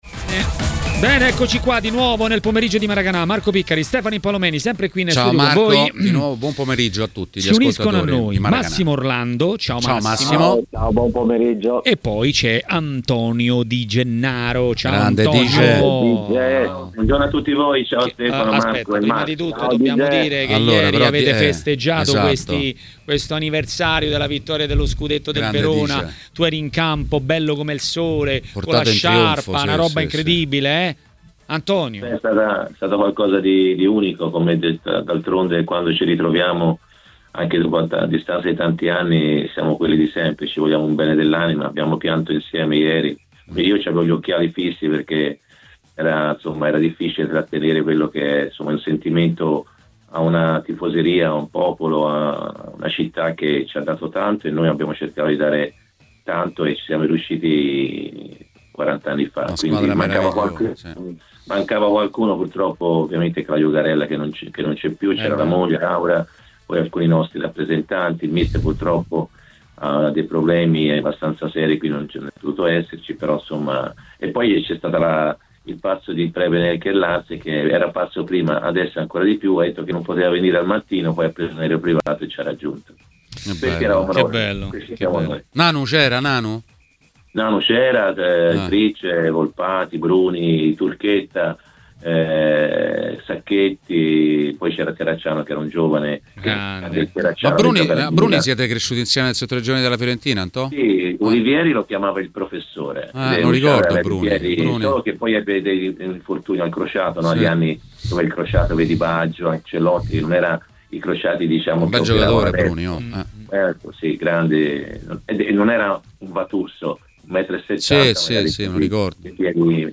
A Maracanà, nel pomeriggio di TMW Radio, è arrivato il momento dell'ex calciatore Massimo Orlando.